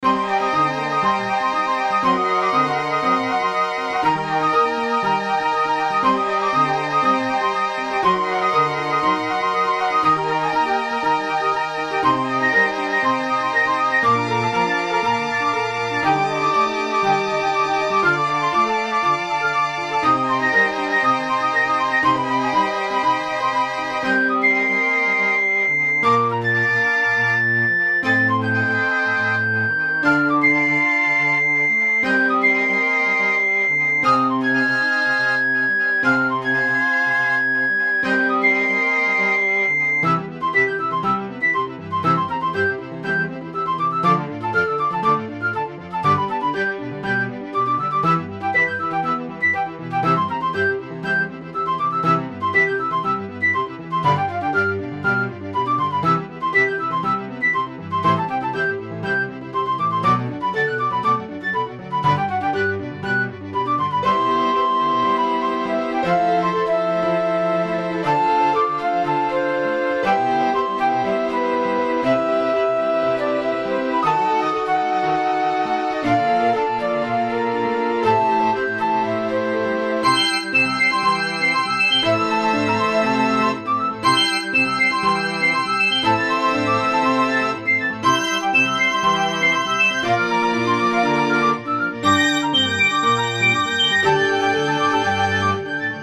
フルート、ピアノ、ビオラ、チェロ
BGM